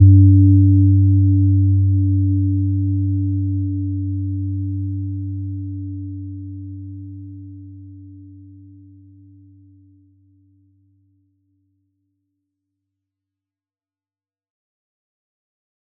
Gentle-Metallic-1-G2-p.wav